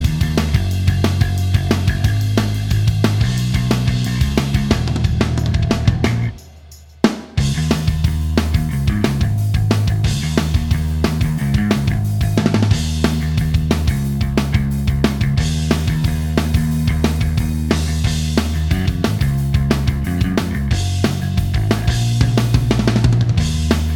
Minus Main Guitar Rock 3:46 Buy £1.50